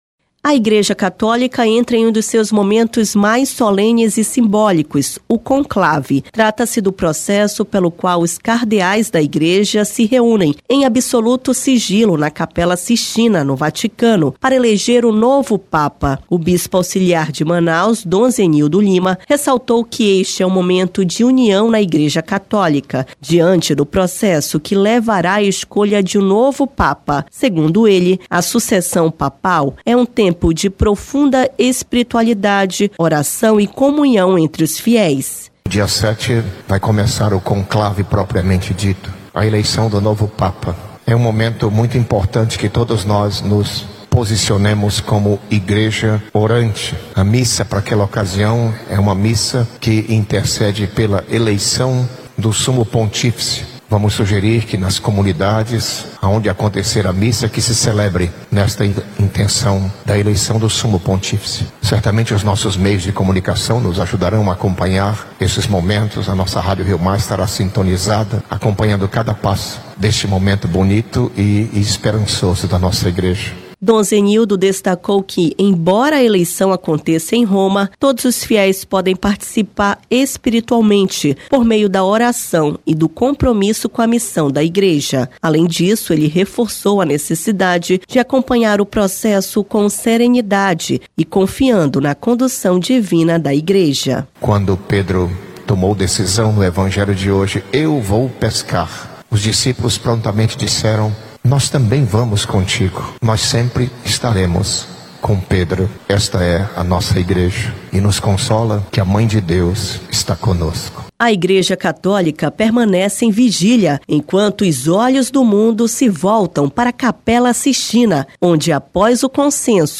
O Bispo Auxiliar de Manaus, Dom Zenildo Lima, ressaltou que este é um momento de união na Igreja Católica, diante do processo que levará à escolha de um novo Papa. Segundo ele, a sucessão papal é um tempo de profunda espiritualidade, oração e comunhão entre os fiéis.